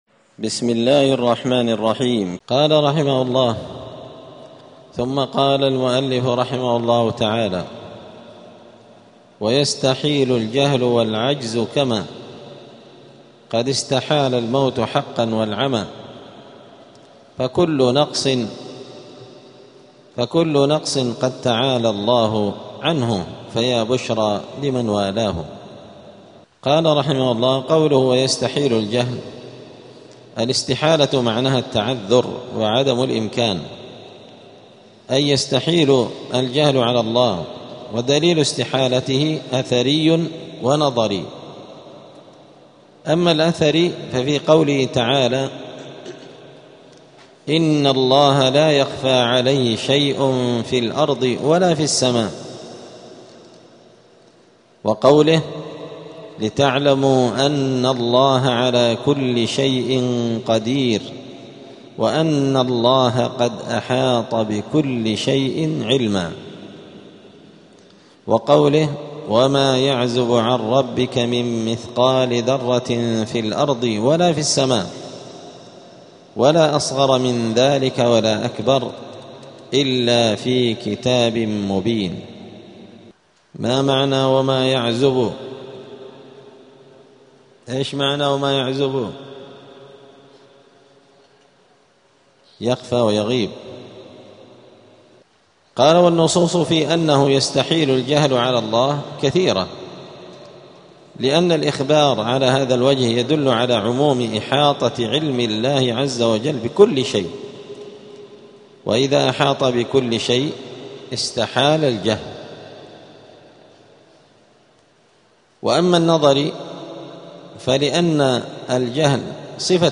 دار الحديث السلفية بمسجد الفرقان قشن المهرة اليمن
54الدرس-الرابع-والخمسون-من-شرح-العقيدة-السفارينية.mp3